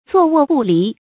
坐臥不離 注音： ㄗㄨㄛˋ ㄨㄛˋ ㄅㄨˋ ㄌㄧˊ 讀音讀法： 意思解釋： 謂時刻相隨。